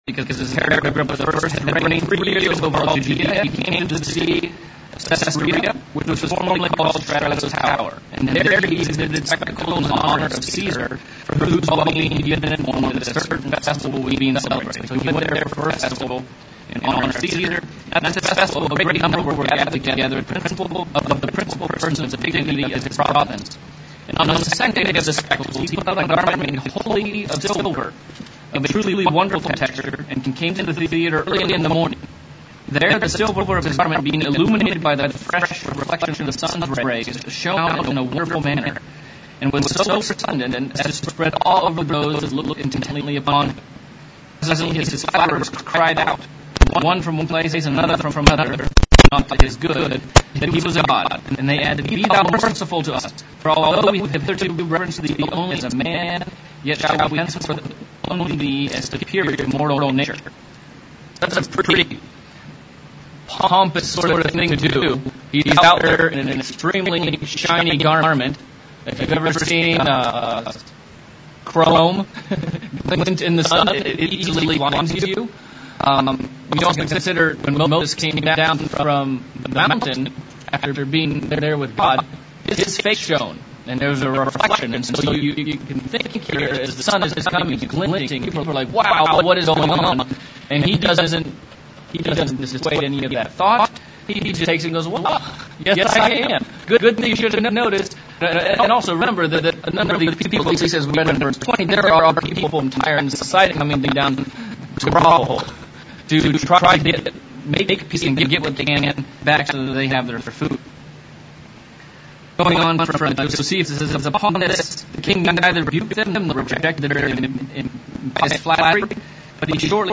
Bible Study: Acts of the Apostles - Chapter 13